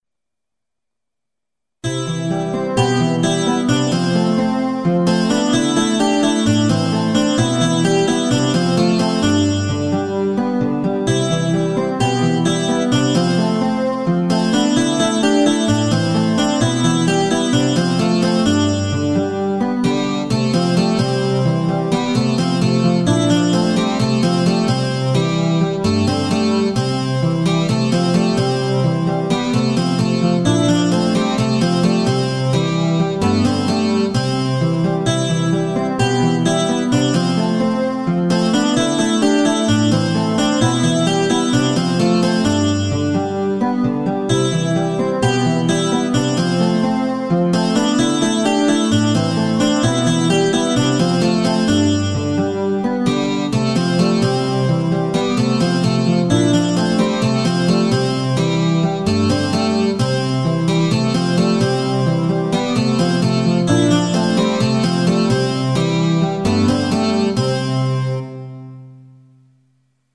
Psalm 9 (V2). Lord, I Will Praise You. A more energtic praise and worship to our protector God. Original Psalm-based Christian Music, including Sheet Music, Lyrics, Lead Sheets, Guitar Chords, Audio.